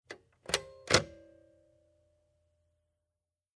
Kinder-Schreibmaschine Bambino
Schwarze Leertaste
0035_Schwarze_Leertaste.mp3